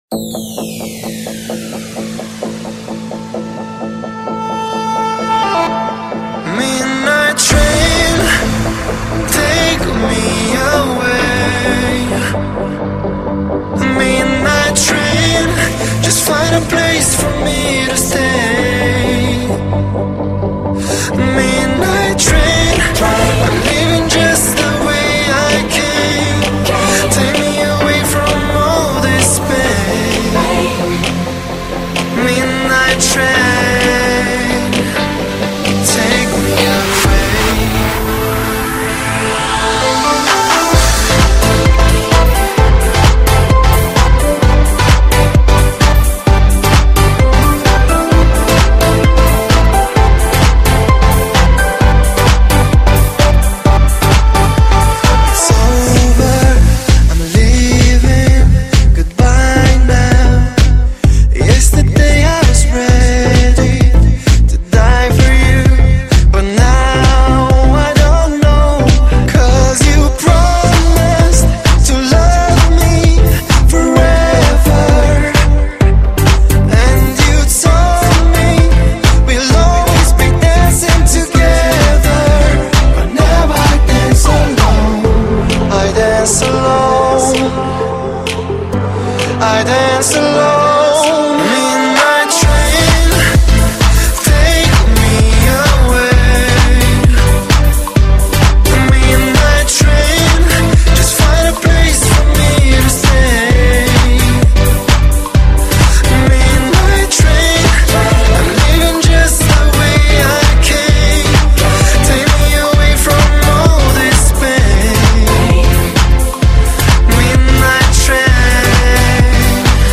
Club | [